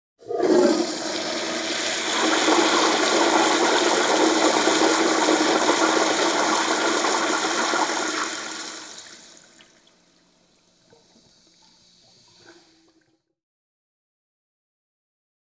Urinal Toilet Flush Sound
household
Urinal Toilet Flush